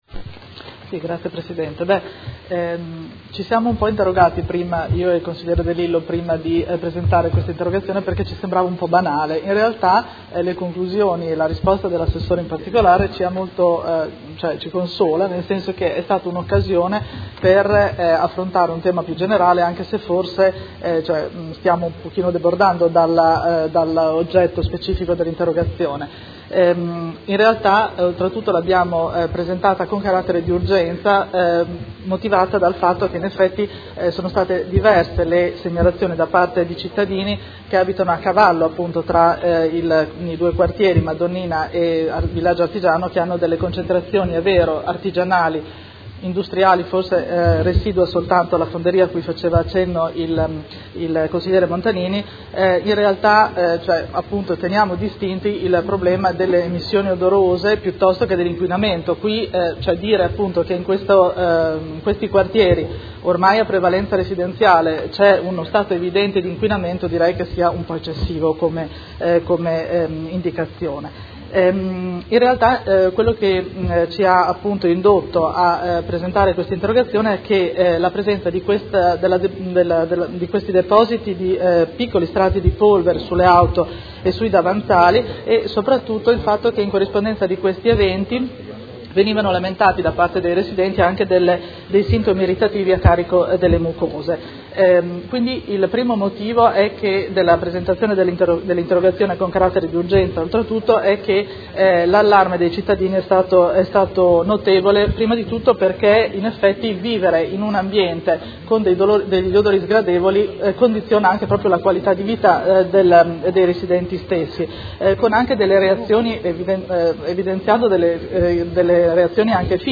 Seduta del 24/11/2016 Interrogazione dei Consiglieri Pacchioni e De Lillo (PD) avente per oggetto: Quartiere Madonnina – Cattivi odori, rilevamenti, monitoraggi e comunicazione. Trasformata in interpellanza su richiesta del Consigliere Montanini.